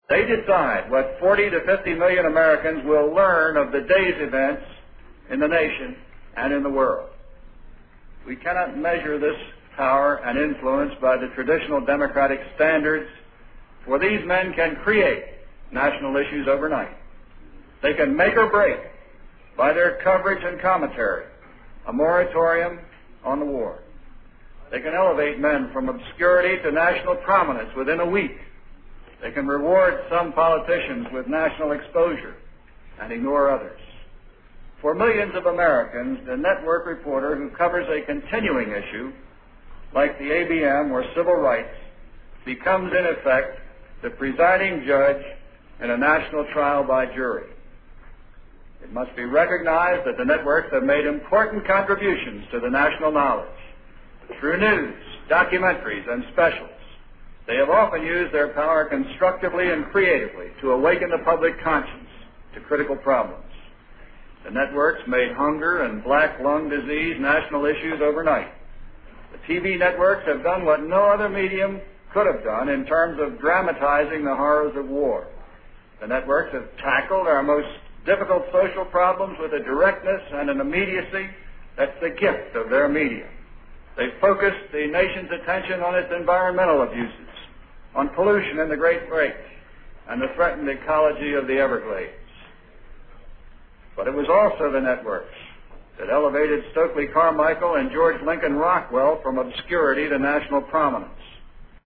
经典名人英语演讲(中英对照):Television News Coverage 6